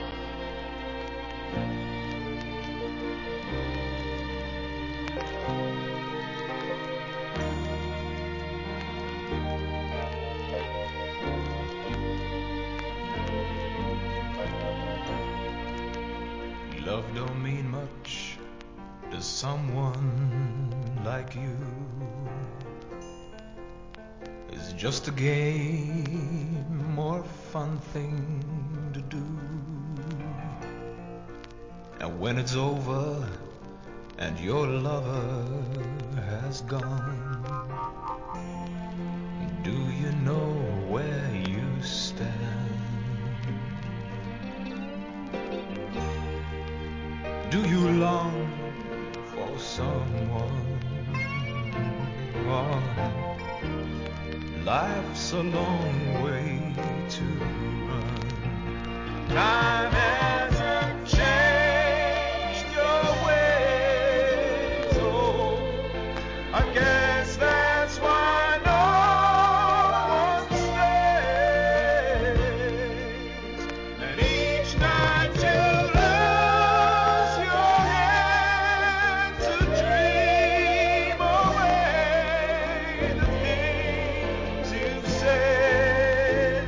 ¥ 1,100 税込 関連カテゴリ SOUL/FUNK/etc...